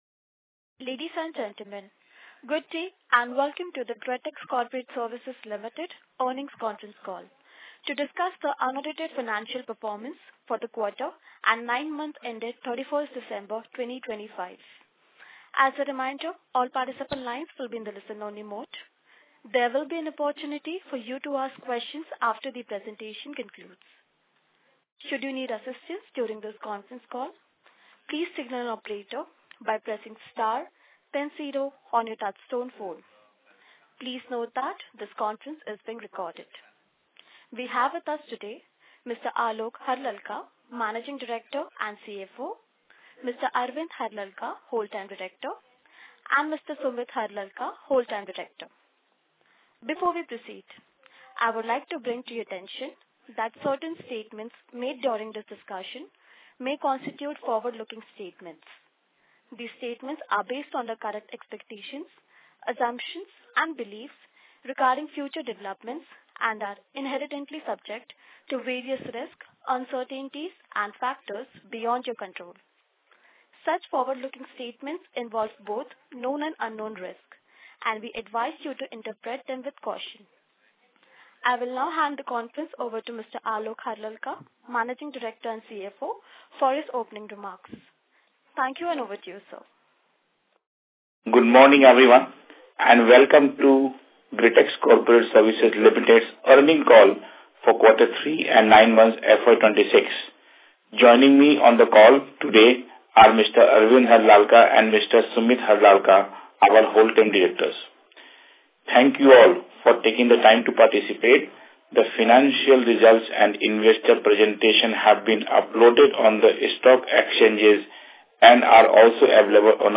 Q3 FY-26 Earnings Call Audio Recording
gretex-coroporate-services-q3-fy26-earnings-call-audio.mp3